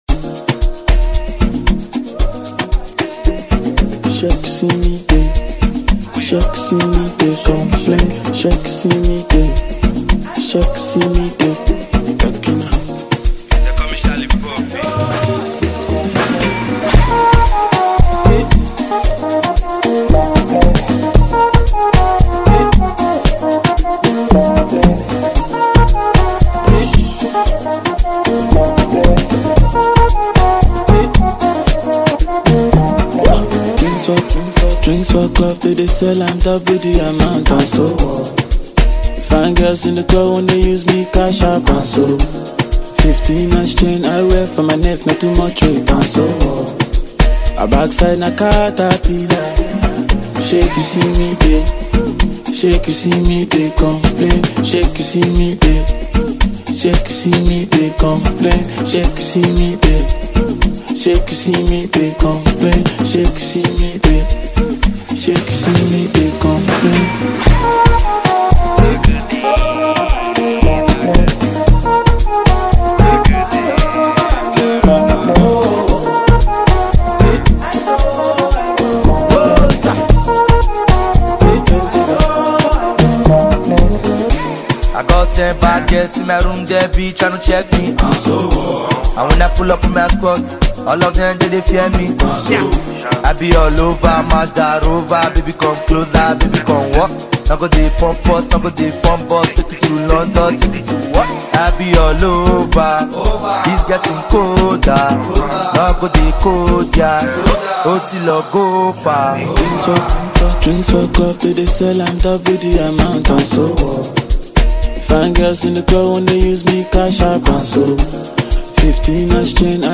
With his unique blend of Afrobeat and contemporary sounds